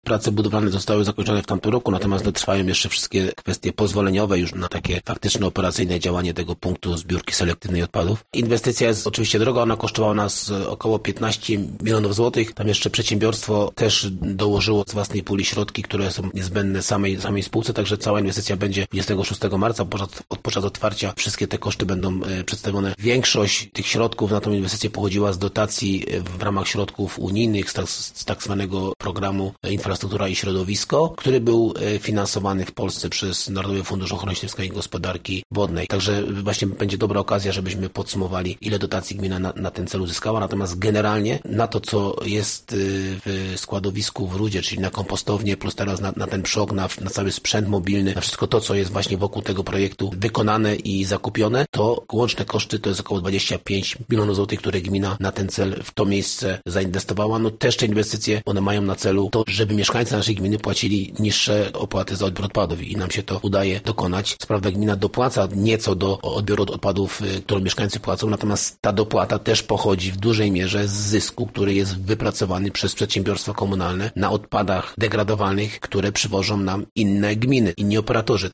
Jeszcze w marcu planowane jest otwarcie obiektu – mówi burmistrz Paweł Okrasa: Prace budowlane zostały zakończone w tamtym roku.